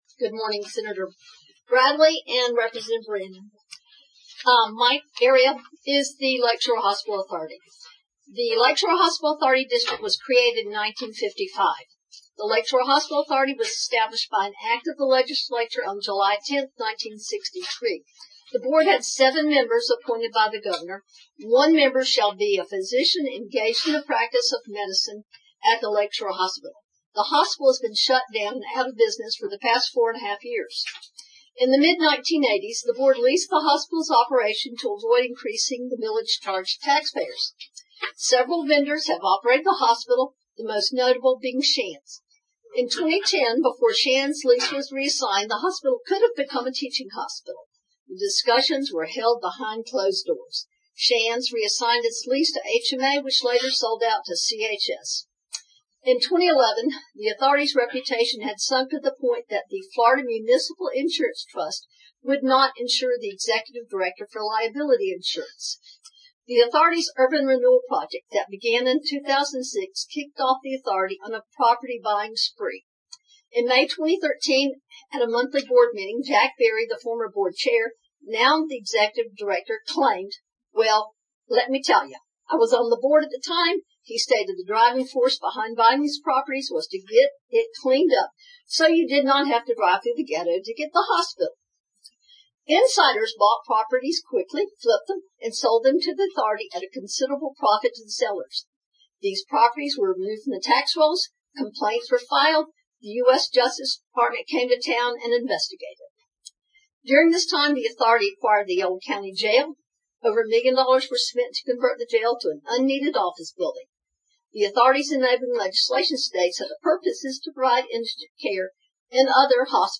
Columbia County's Legislative Delegation meeting played to a packed house.
addresses the Legislative Delegation.